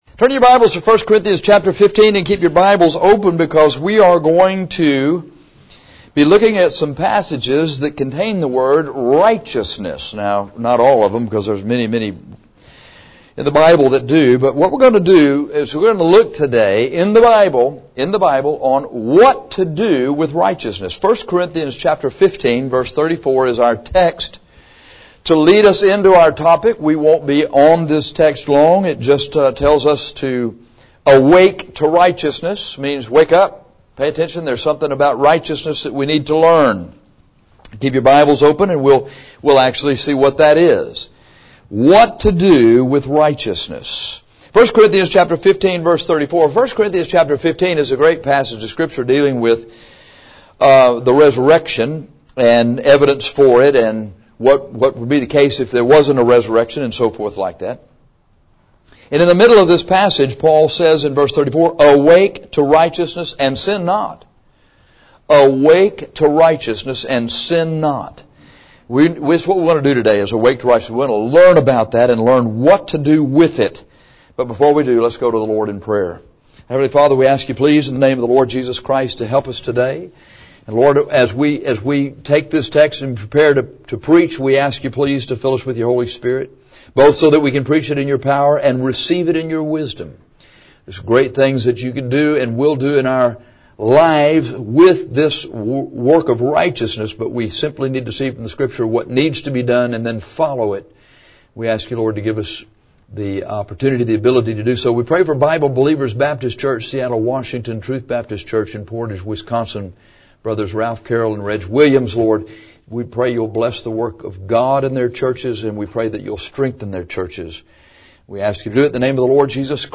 This sermon tells you what to do with righteousness. You are to seek, appropriate, pursue instruction in, yield to, follow after, and preach righteousness.